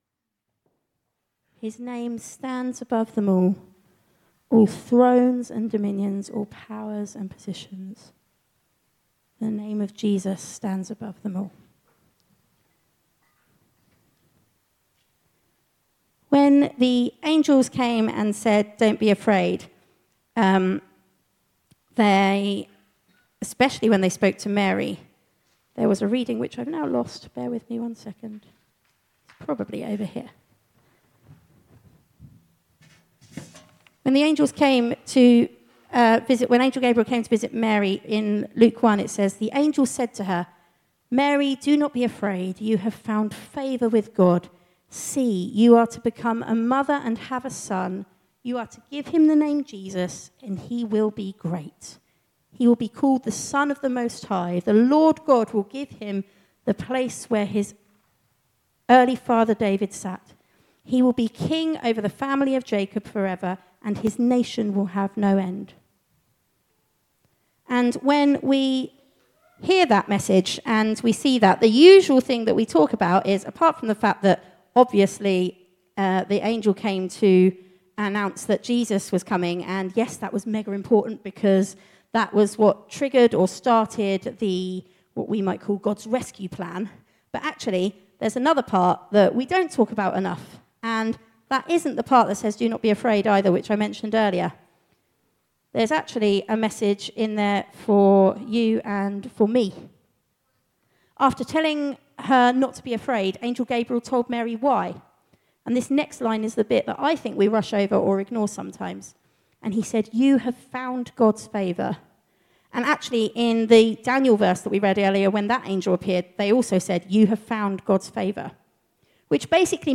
From Series: "Stand Alone Sermons 2024"